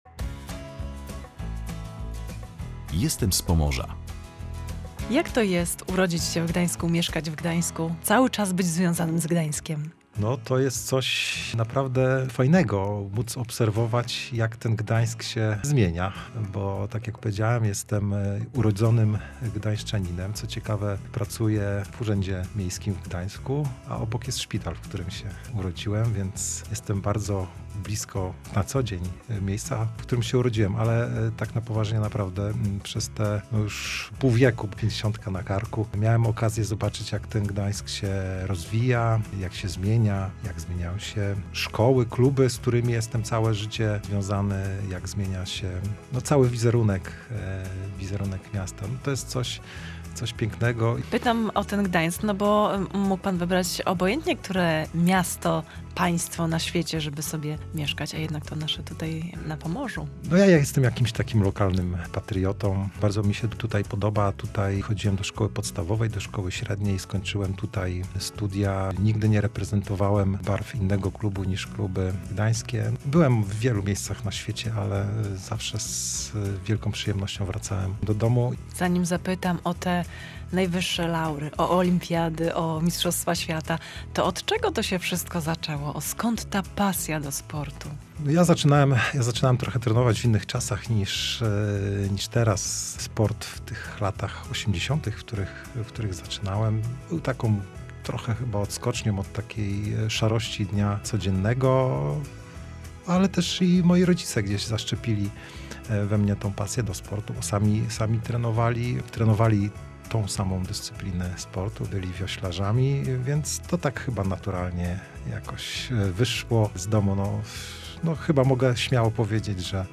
Rozmawiamy z Adamem Korolem